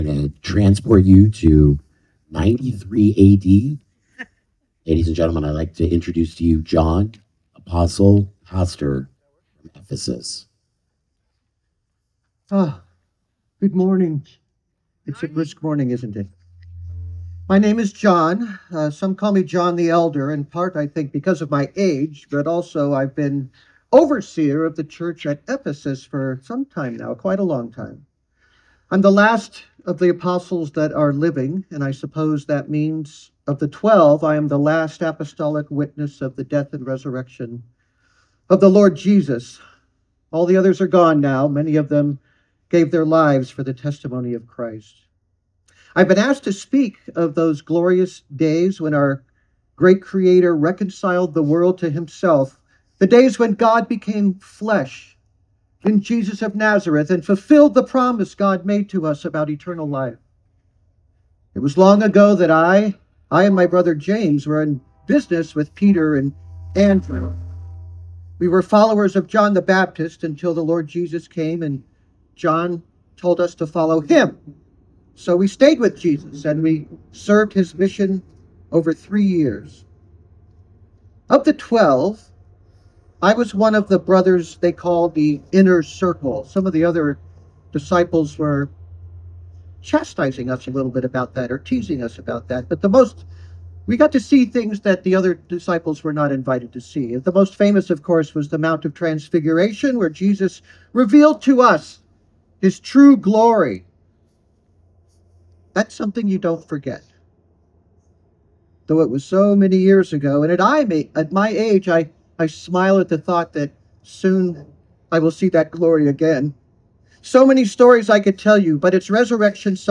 Listen in as we have a visit from John, Apostle & Pastor, who tells his view of the crucifixion and resurrection of Jesus. This is from the Sunrise Service, 2026.
Sunrise-Service.mp3